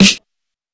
Snares
Smk_Sn.wav